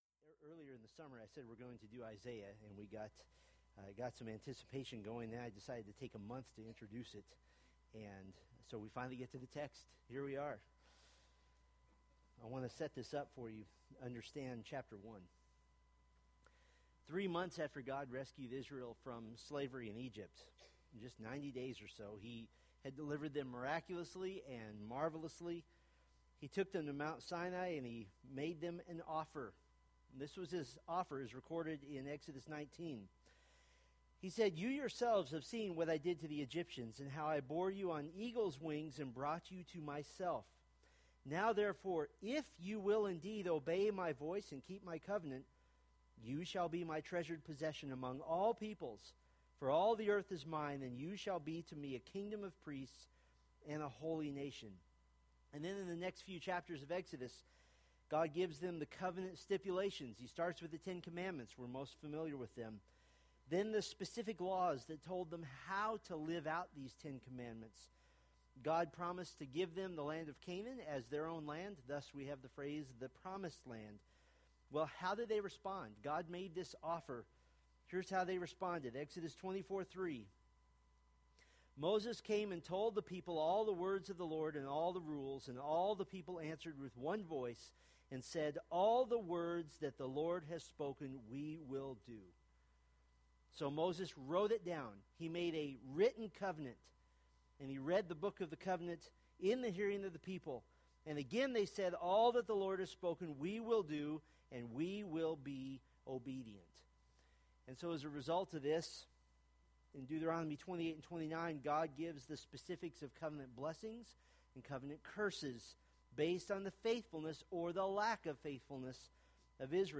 Preached October 11, 2015 from Isaiah 1:2-31